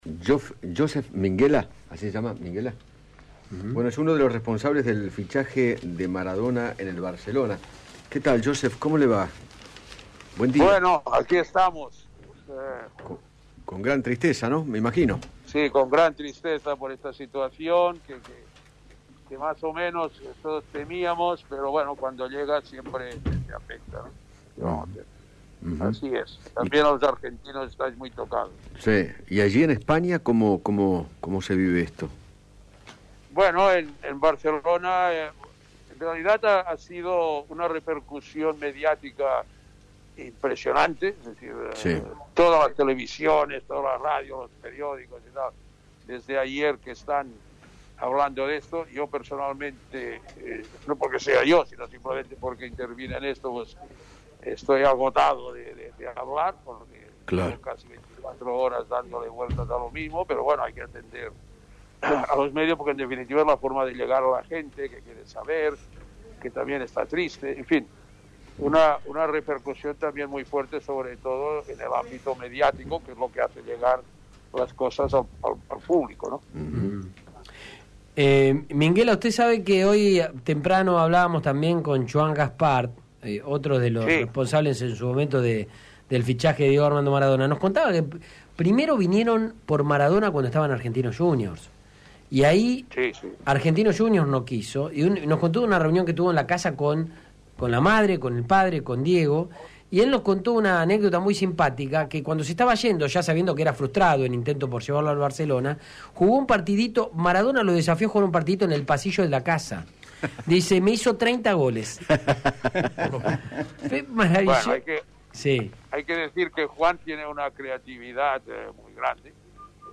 Josep Maria Minguella, representante de jugadores y quien llevó a Maradona a jugar al Barcelona, dialogó con Eduardo Feinmann sobre la repercusión que generó su fallecimiento en aquella Ciudad y contó cómo fue la experiencia del diez en el club catalán.